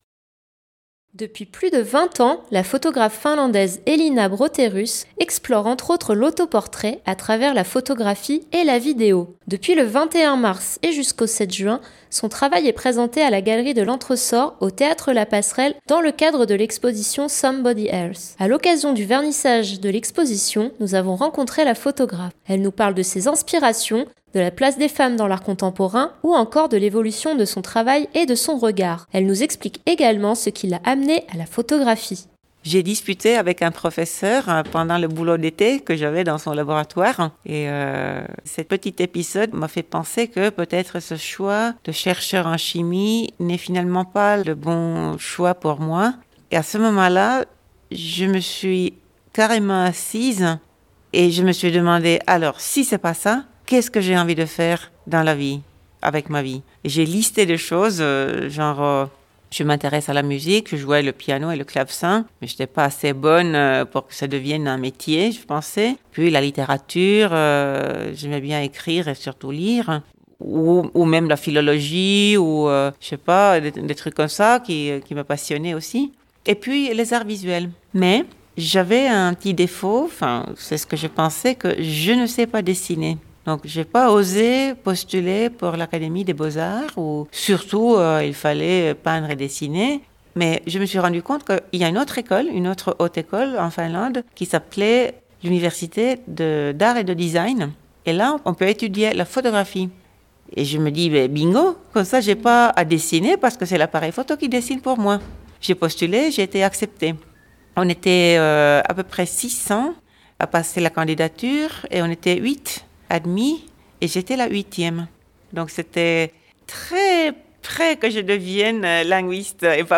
À l’occasion du vernissage de l’exposition, nous avons rencontré la photographe : elle nous parle de ses inspirations, de la place des femmes dans l’art contemporain, ou encore de l’évolution de son travail et de son regard.